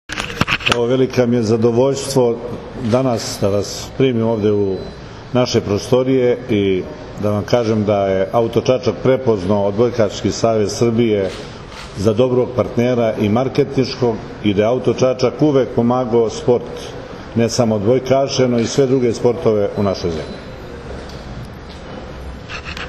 U prostorijama kompanije “Auto Čačak” na Novom Beogradu, danas je svečano potpisan Ugovor o saradnji između kompanije “Auto Čačak” i Odbojkaškog Saveza Srbije.
IZJAVA